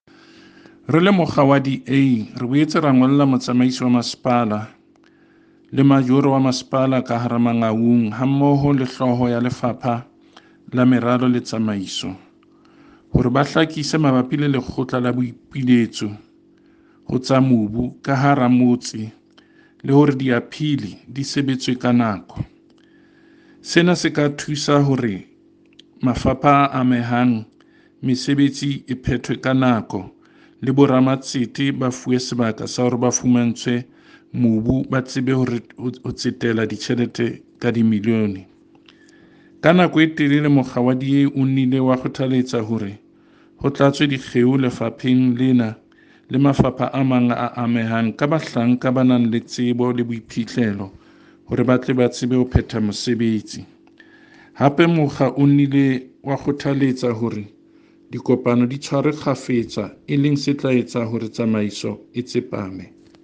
Sesotho by Cllr David Masoeu.